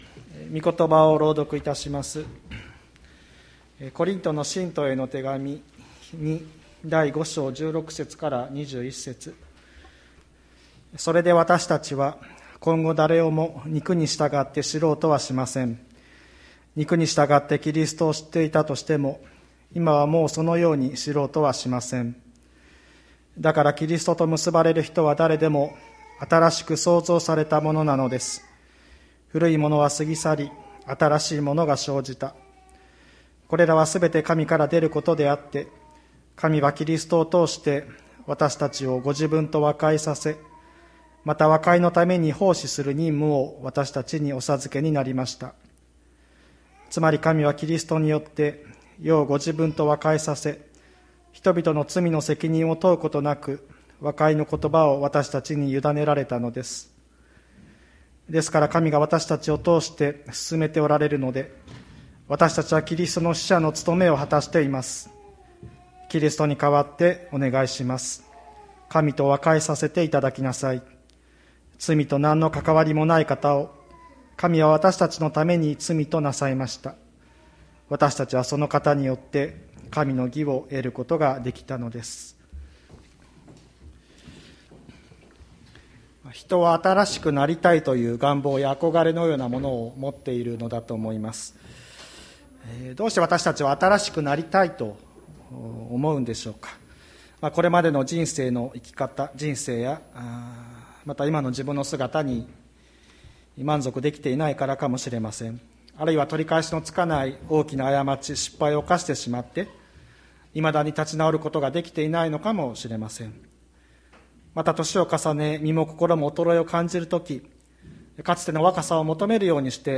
2021年04月25日朝の礼拝「わたしたちは和解の使者」吹田市千里山のキリスト教会
千里山教会 2021年04月25日の礼拝メッセージ。